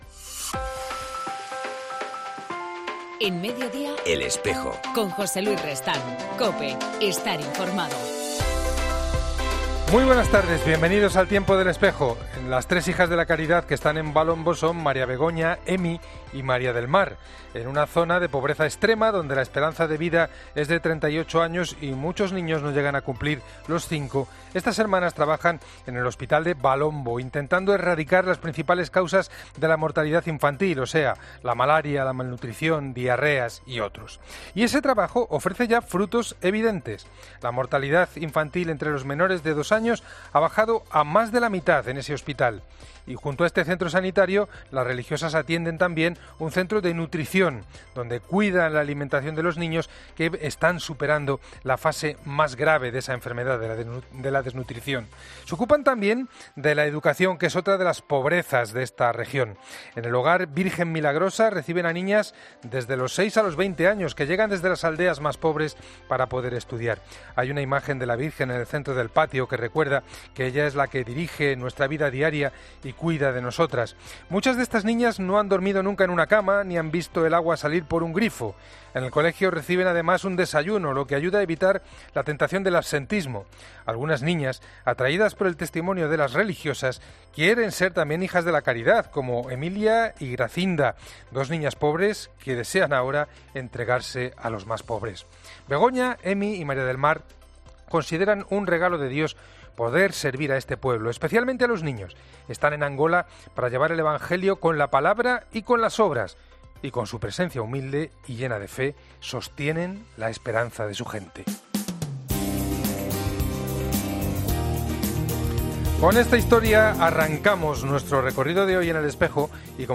El Espejo En EL Espejo del 30 de abril hablamos del proyecto Puente Esperanza Hoy hablamos de la Asociación Puente Esperanza, que surge de la iniciativa de un grupo de mujeres de diferentes congregaciones religiosas que residen en el distrito madrileño de Tetuán, para dar una respuesta a la realidad de precariedad con la que se encuentran las personas de origen extranjero. Hoy en El Espejo hablamos con